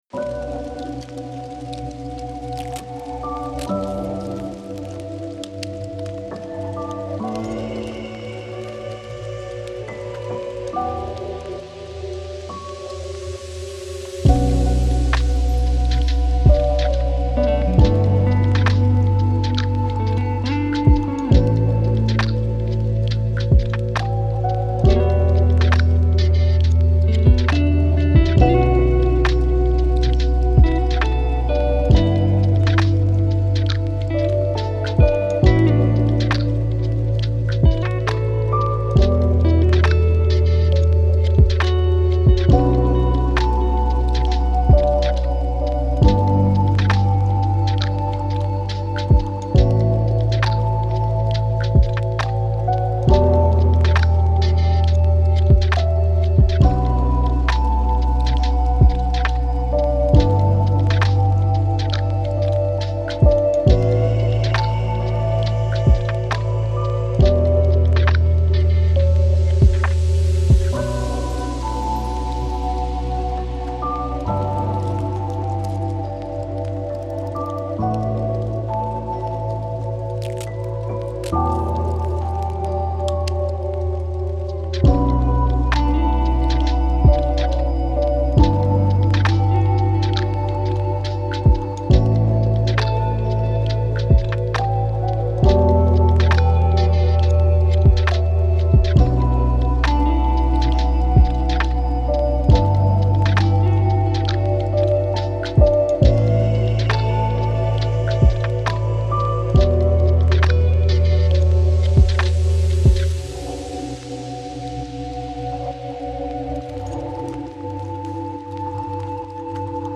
آرامش بخش , تخیلی و رویایی , موسیقی بی کلام